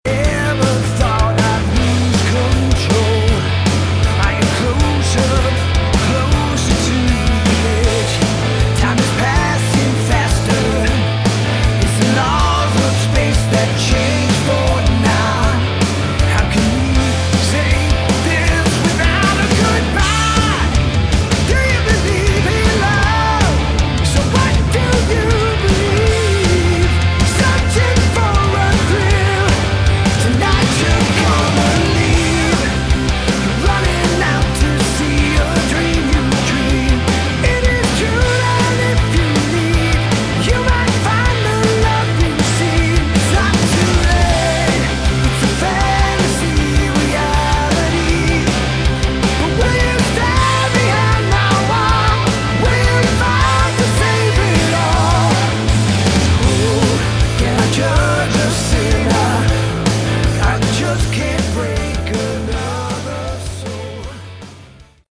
Category: Modern Hard Rock
lead vocals, drums
guitars
bass